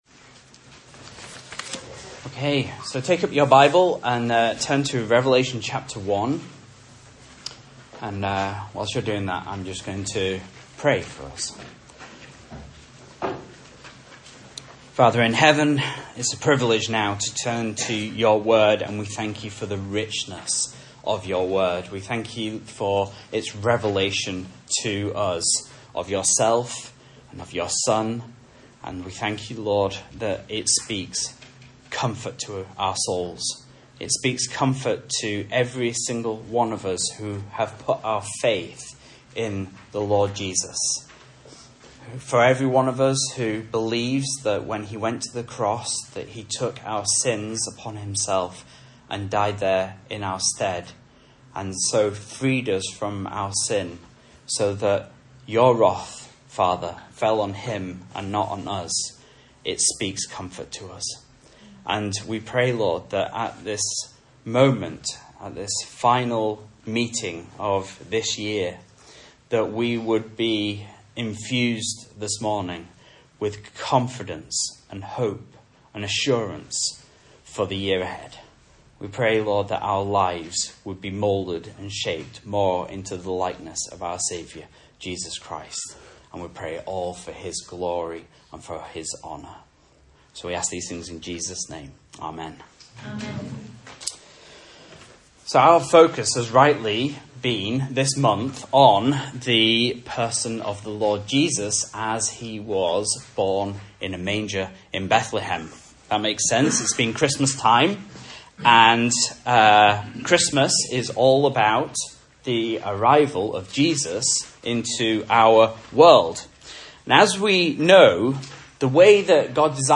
Message Scripture: Revelation 1